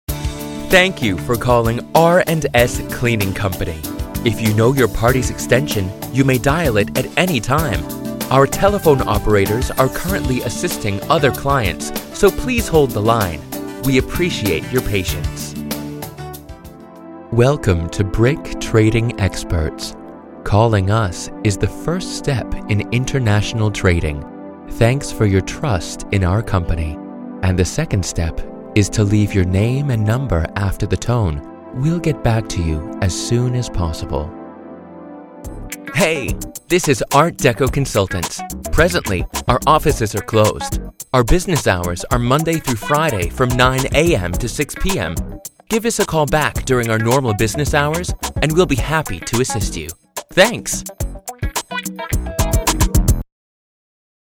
STUDIO: ISDN Centauri 3001 II Aptx Codec, Source Connect, Neumann TLM 103, TL Audio Ivory II Preamp, ProTools
Native US Sprecher mit einen freundlichen, frechen, guy next door Stimme für Werbung.
englisch (us)
Sprechprobe: Sonstiges (Muttersprache):